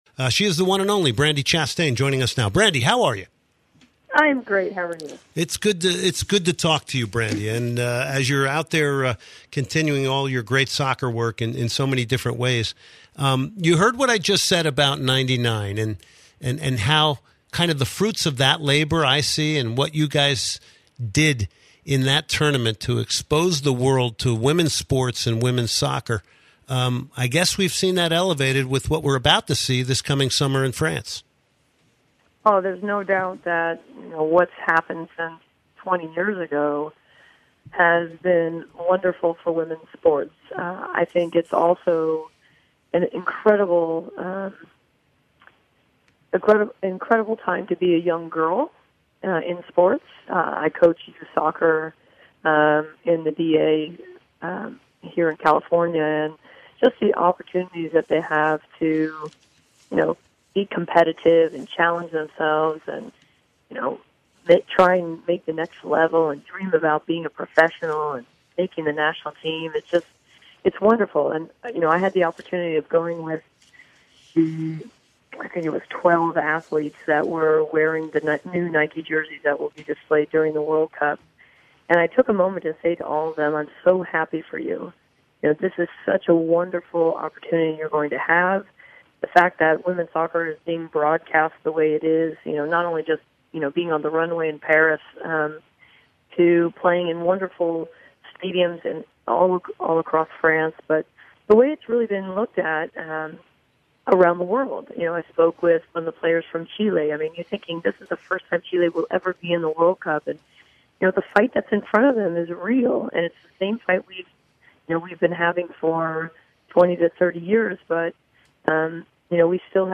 04/02/2019 Soccer Matters Interview- Brandi Chastain